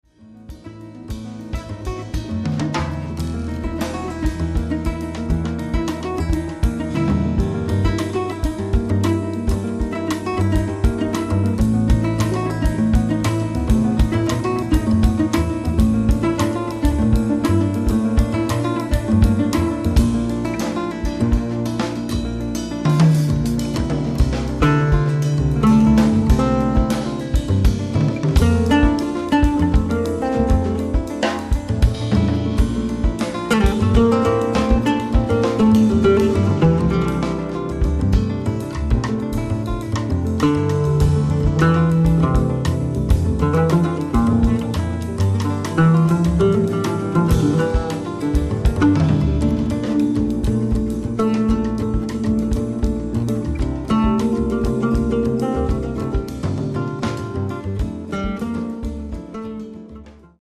voice, oud